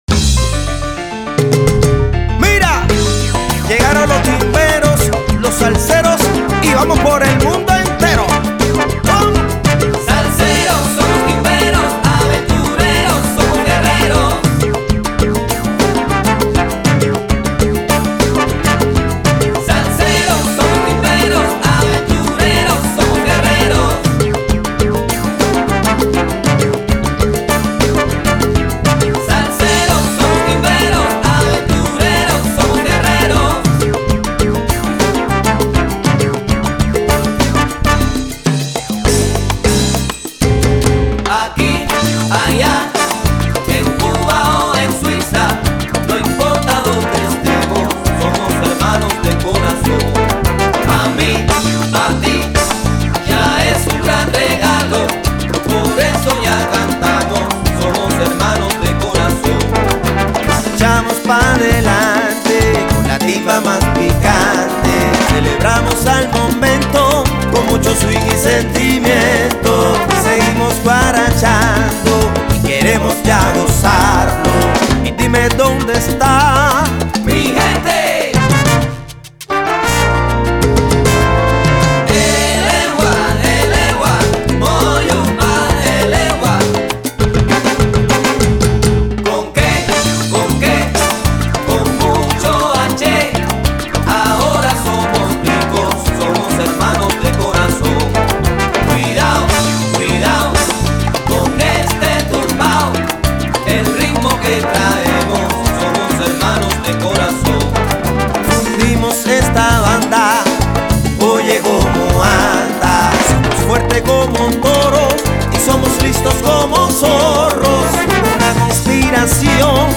трубач.